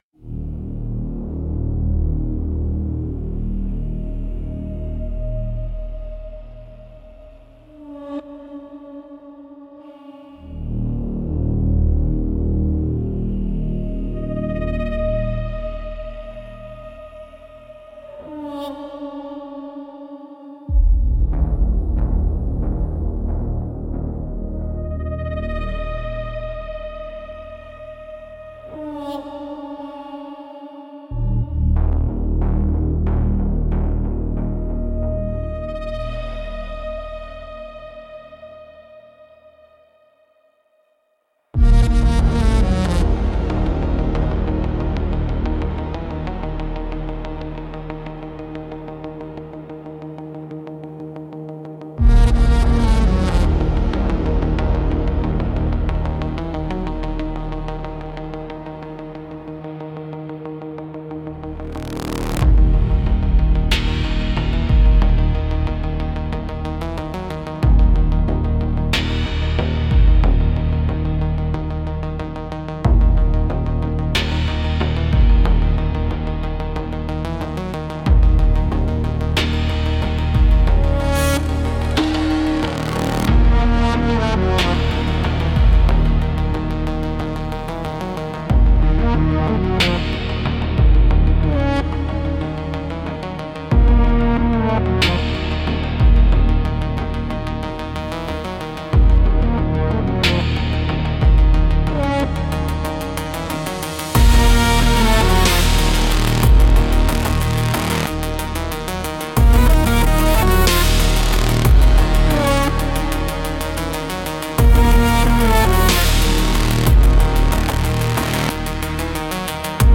Instrumental - As the Last Light Falters 4.18